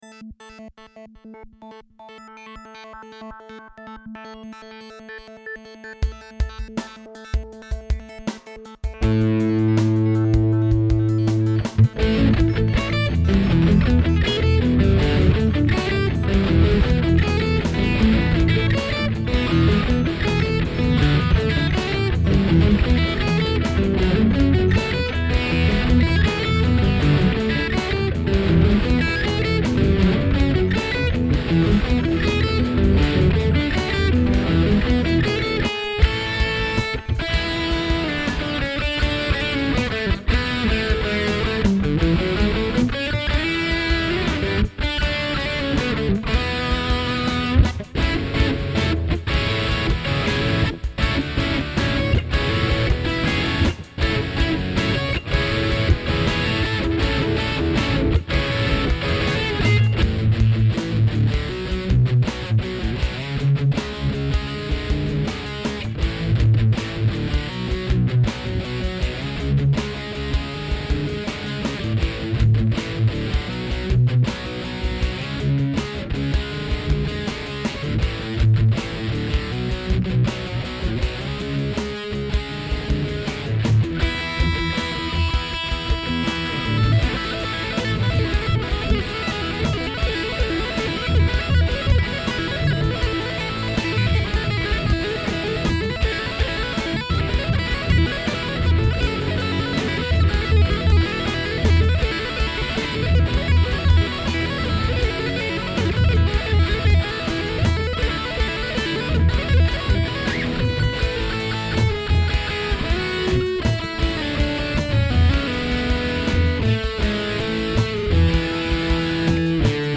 A guitarist
Positive vibes, simple stuff but catchy. I really like how the harmony works here: it gives me the goosebumps when the taping part ends and overdub guitar merges in the resolution of the song.